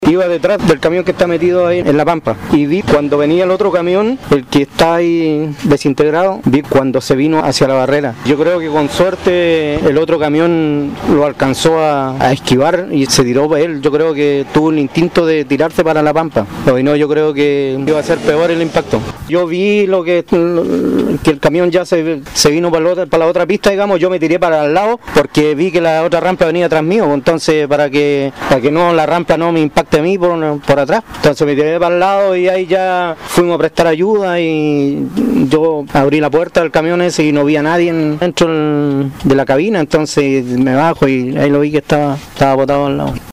Un testigo presencial del accidente narró el momento que le tocó vivir cuando vió cómo un camión cruza las barreras de contención e impacta a alta velocidad al otro móvil de carga, que circulaba en sentido contrario en la ruta concesionada.